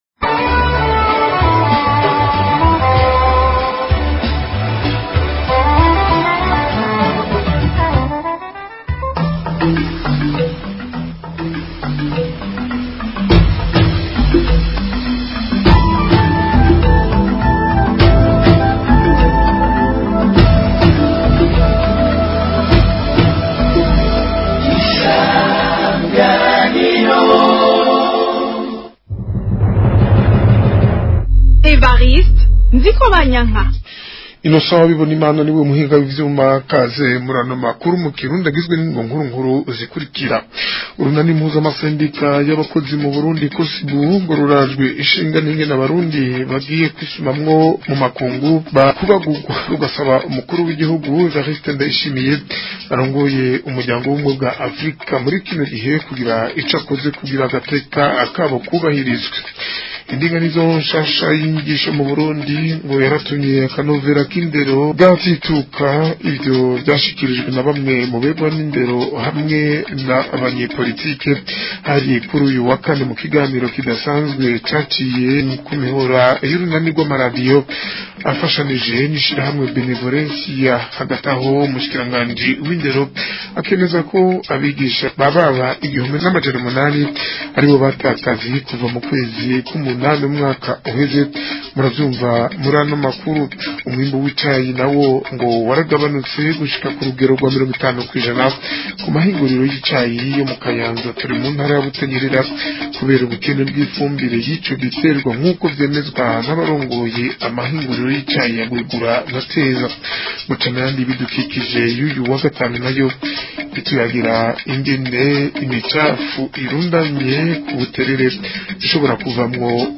Amakuru yo ku wa 20 Ruhuhuma 2026